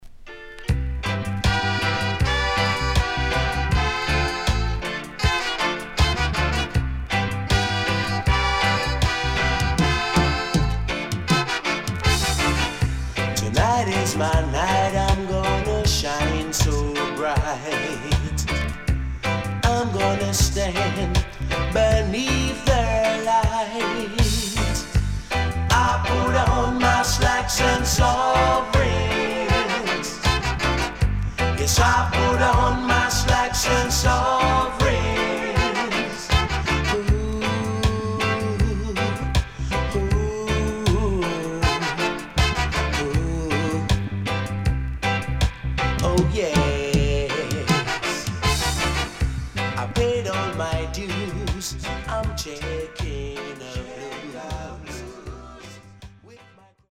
【12inch】
SIDE A:少しチリノイズ入ります。